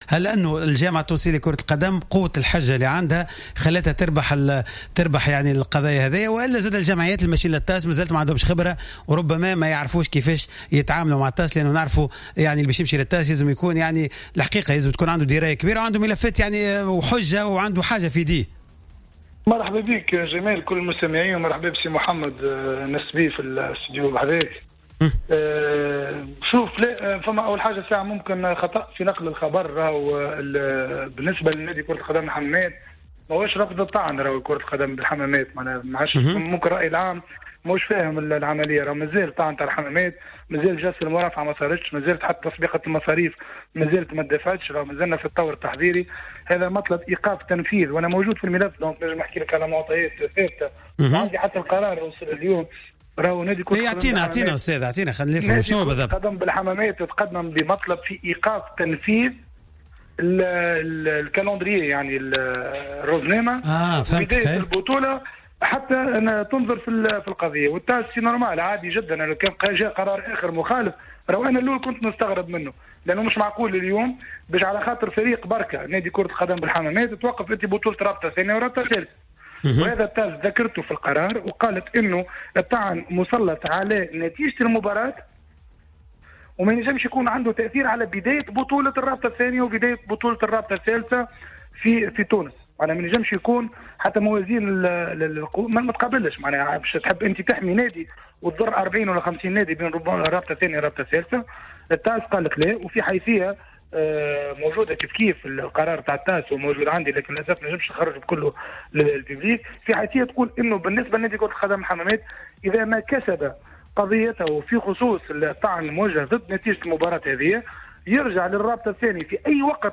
مداخلة